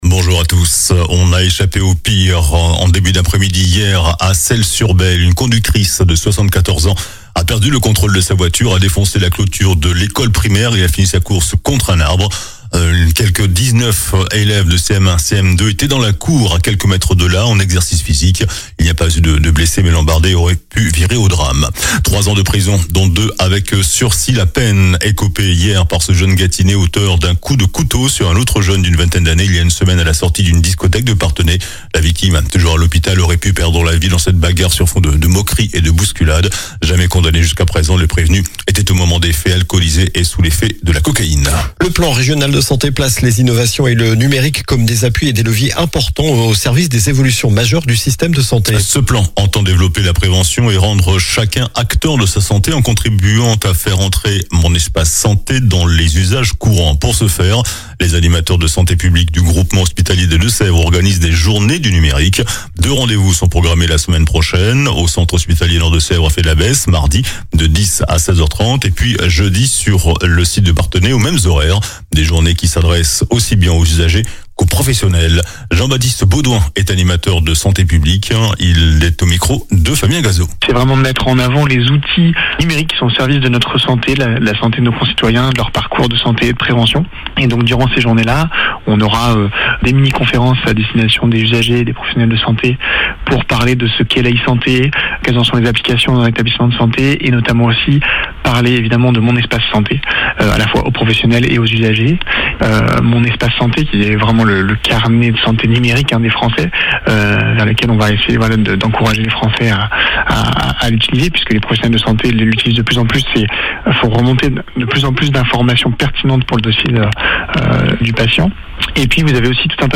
Journal du samedi 13 septembre